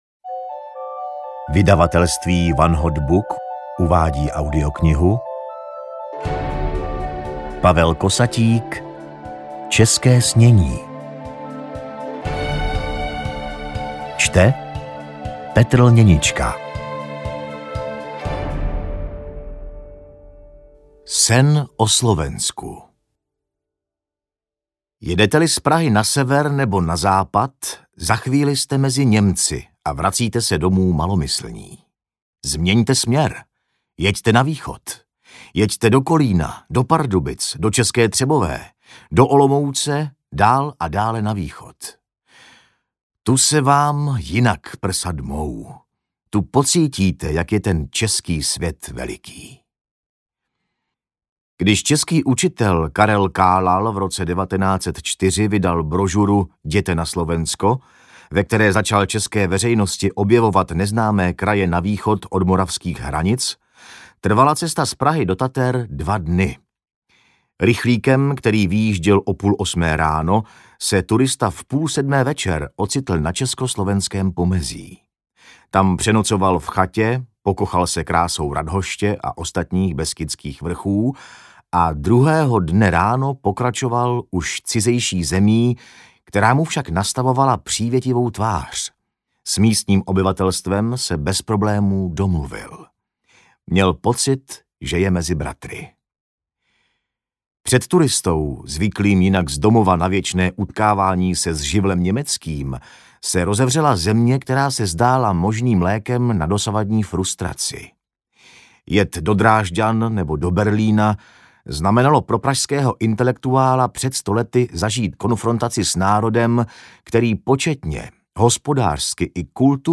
České snění audiokniha
Ukázka z knihy